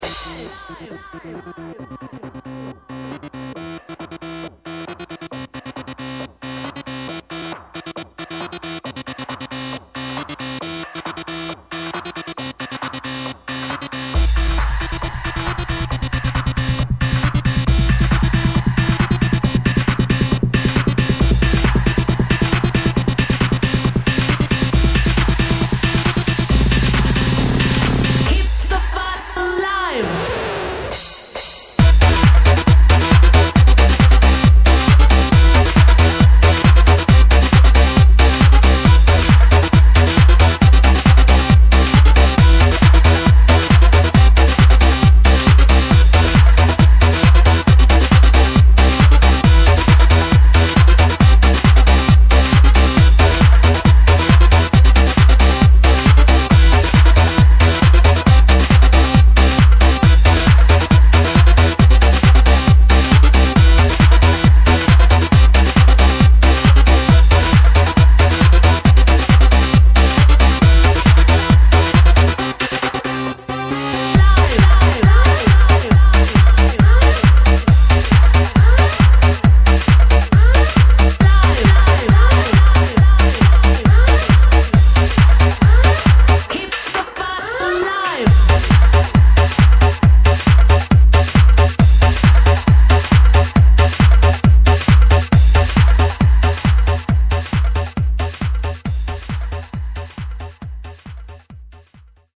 Progressive House, Techno